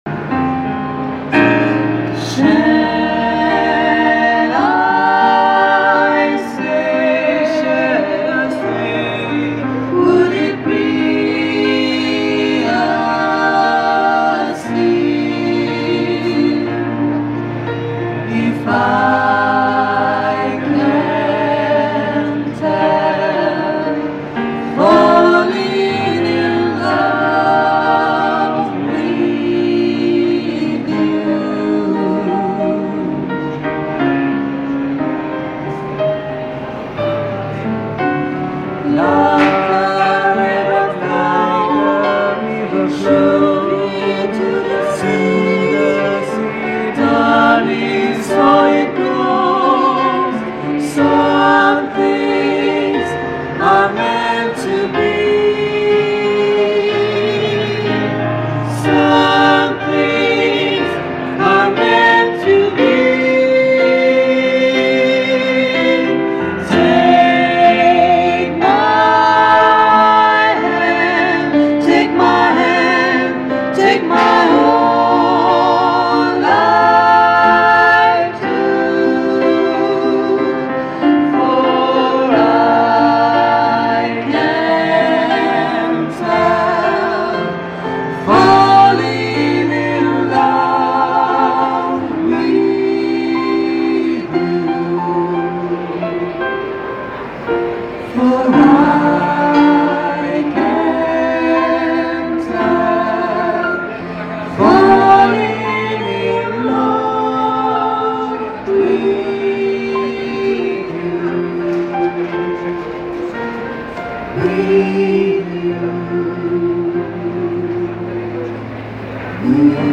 Audio Live
performance live
coro gospel